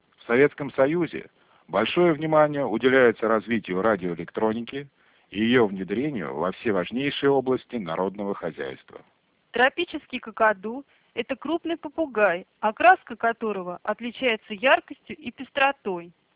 По следующим звуковым файлам вы сможете оценить качество алгоритма G.729.D:
Речь, упакованная кодером G.729.D и восстановленная декодером G.729.D при нулевых потерях
fspeech_g729d.wav